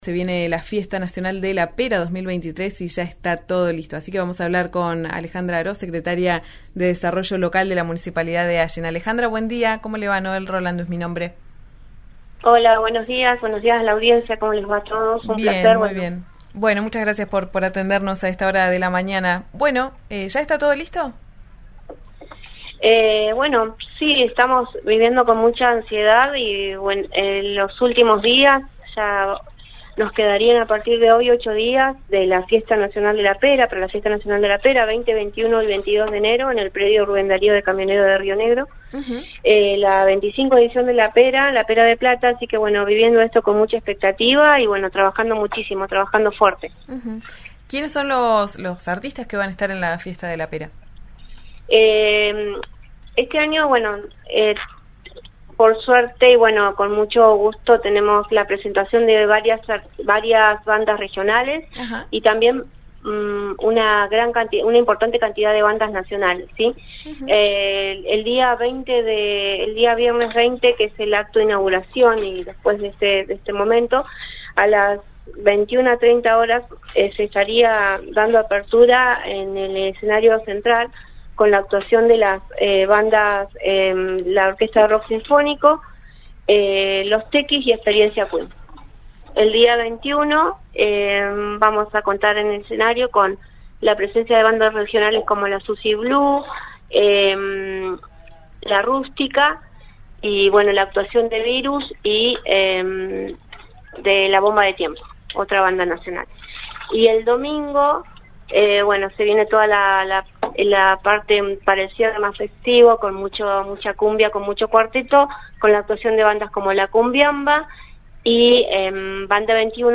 Escuchá a Alejandra Aros, secretaria de Desarrollo Local de la Municipalidad de Allen, en «Ya es tiempo» por RÍO NEGRO RADIO: